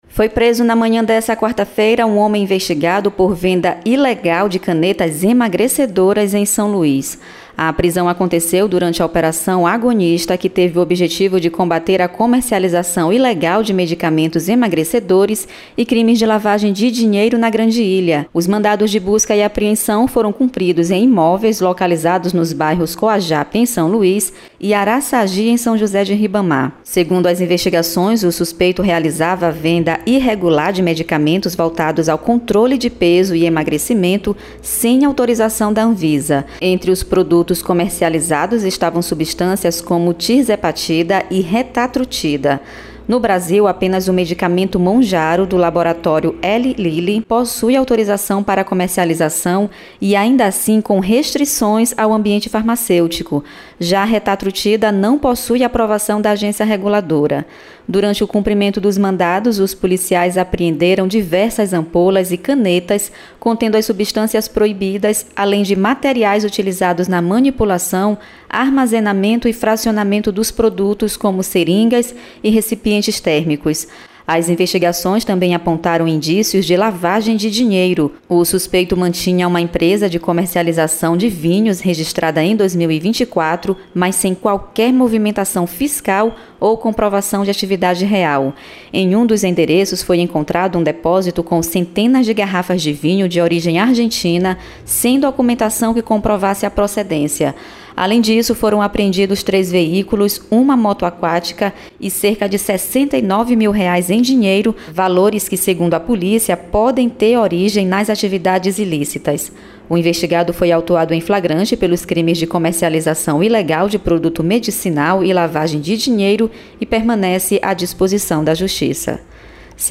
Matérias em Áudio